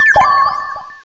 pokeemerald / sound / direct_sound_samples / cries / phione.aif